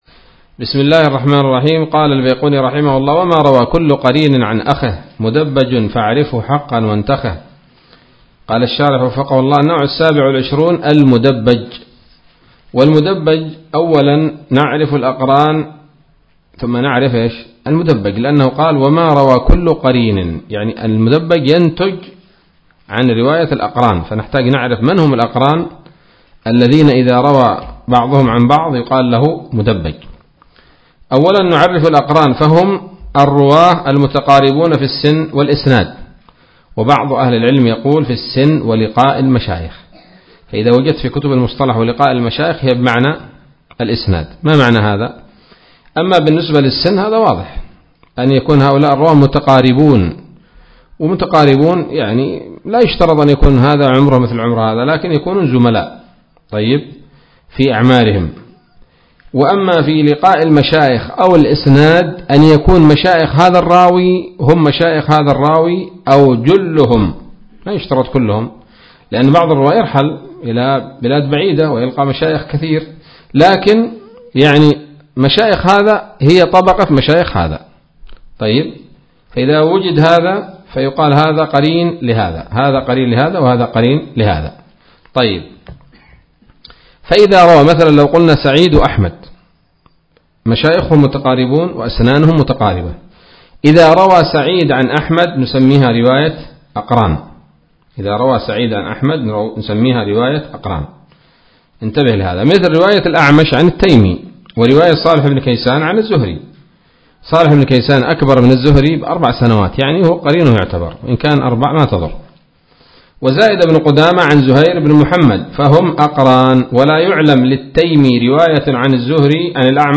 الدرس الحادي والثلاثون من الفتوحات القيومية في شرح البيقونية [1444هـ]